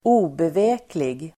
Uttal: [²'o:beve:klig]